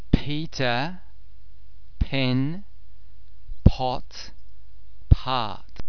More particularly, the word initial English [p] is produced with a lot of aspiration; that is, a significant amount of air blow follows the production of the sound.
·English words pronounced by the English speaker :